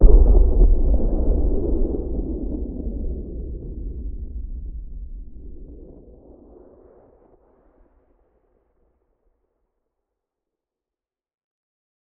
0335ec69c6 Divergent / mods / Soundscape Overhaul / gamedata / sounds / ambient / soundscape / underground / under_3.ogg 66 KiB (Stored with Git LFS) Raw History